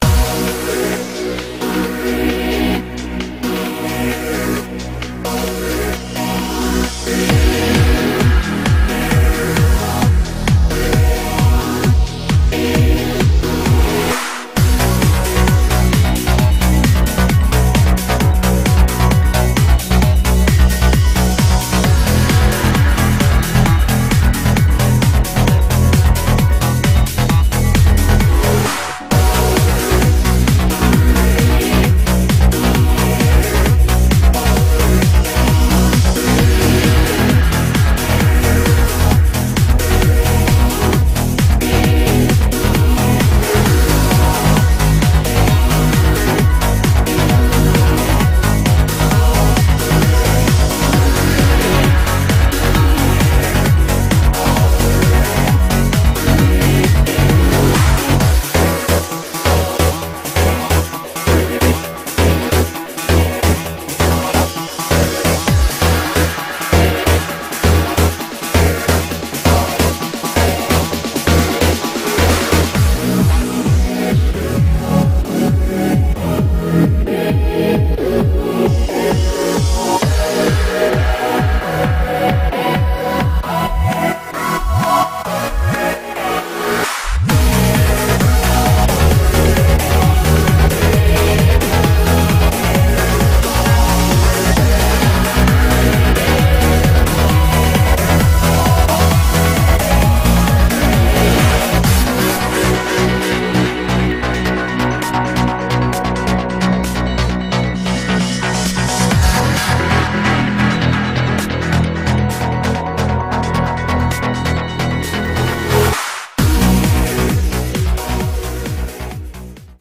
BPM66-264
Audio QualityPerfect (High Quality)